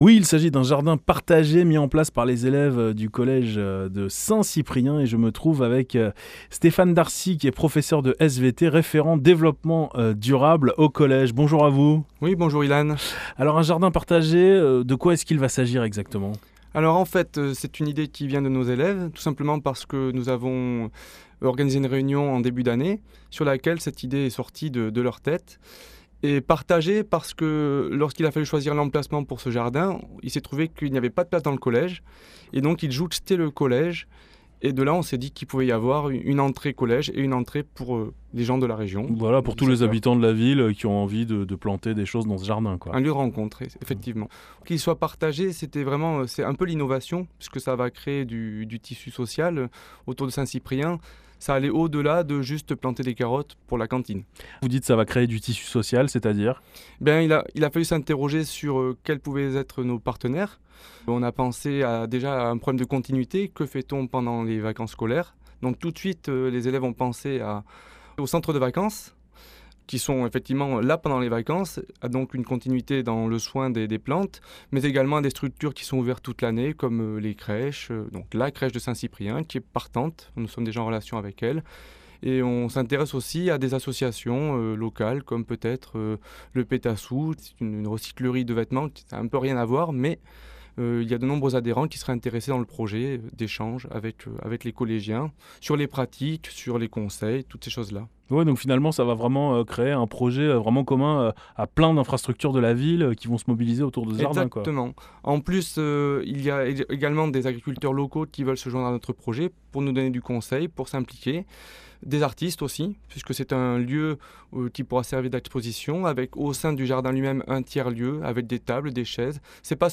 Interviews France Bleu Périgord